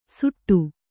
♪ suṭṭu